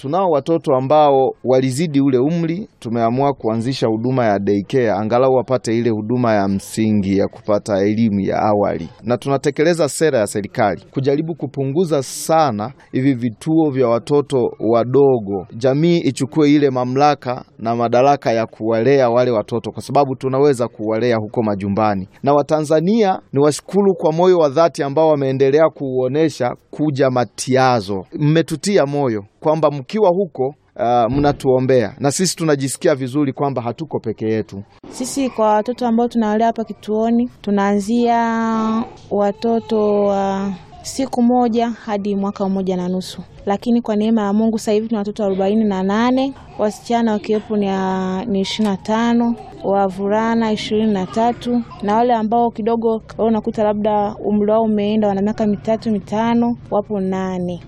Baadhi ya watumishi wanaolea watoto hao